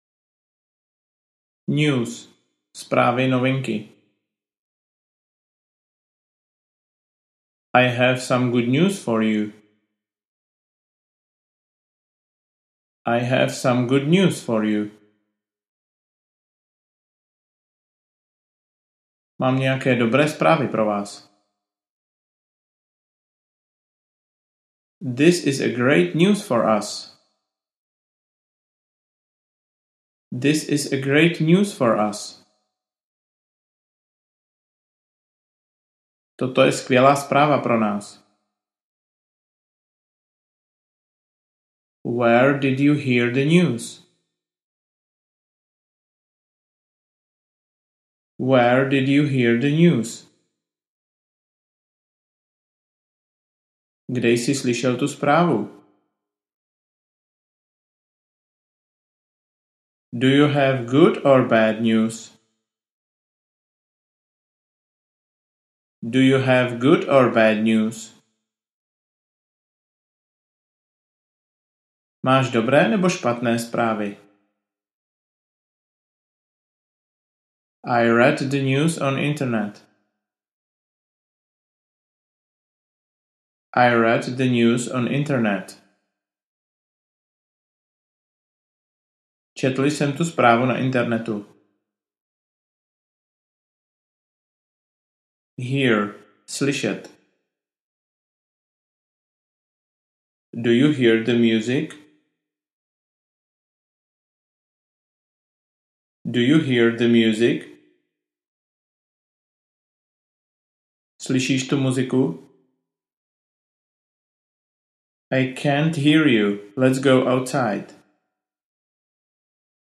Anglická slovíčka - úroveň 2 pro mírně pokročilé audiokniha
Ukázka z knihy
Audiokniha vás učí poslouchat jednoduché věty pořád dokola a to dlouho, až přes 8 hodin.